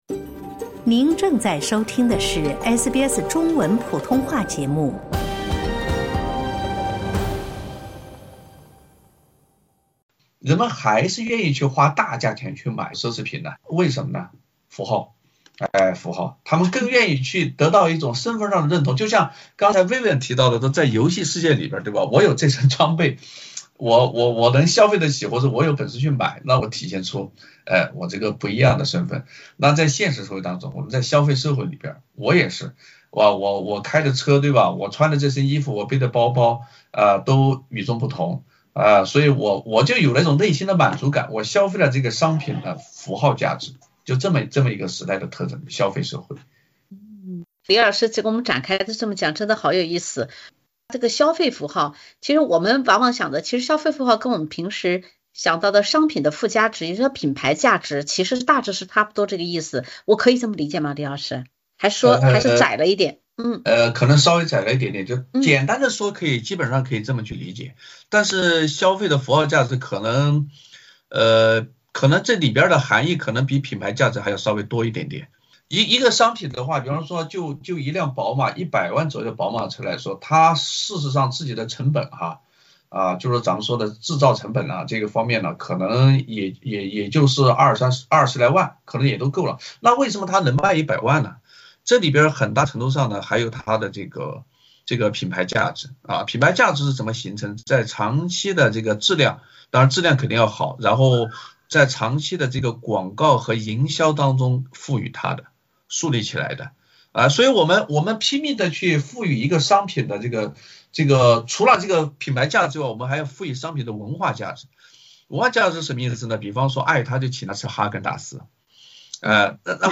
嘉宾一起吐槽、剖析、提醒：别让你的钱包，替别人的意义买单。